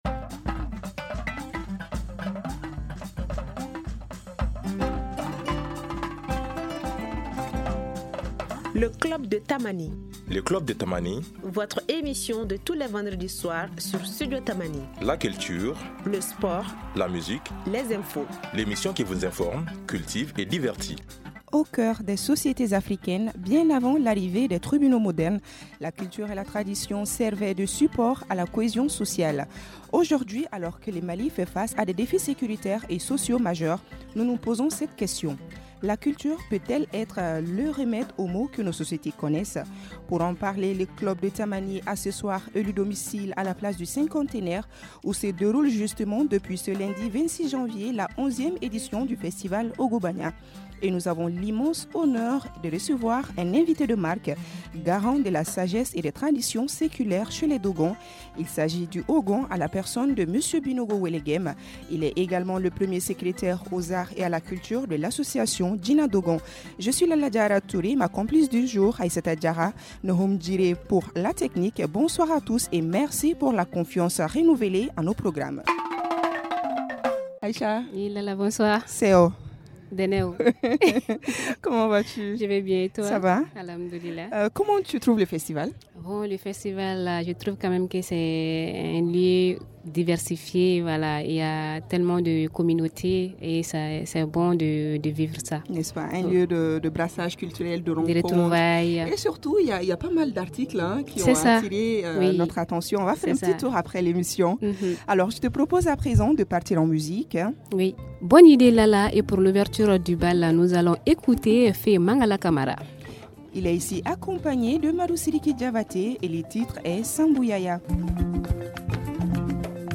en direct de la 11e édition du festival Ogobagna à la place du cinquantenaire.